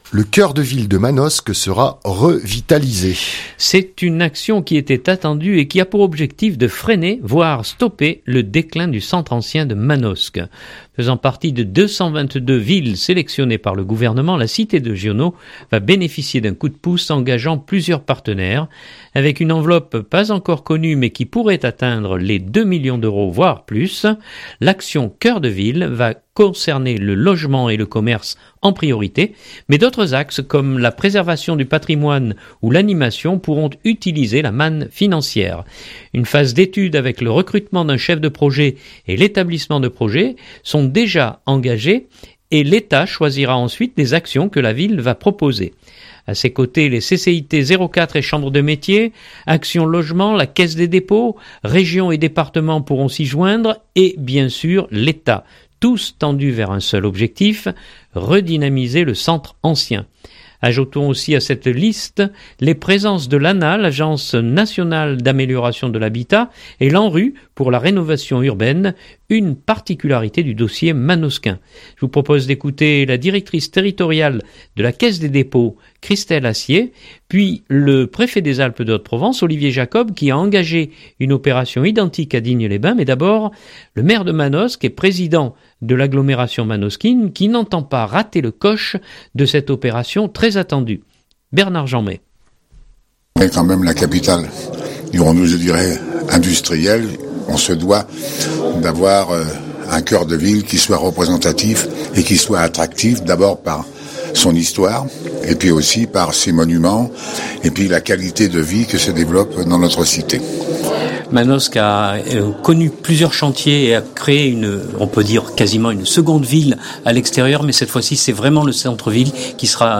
le Préfet des Alpes de Haute-Provence, Olivier Jacob qui a engagé une opération identique à Digne-les-Bains, mais d’abord, le maire de Manosque et président de l’agglomération manosquine qui n’entend pas rater le coche de cette opération très attendue.